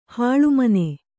♪ hāḷu mane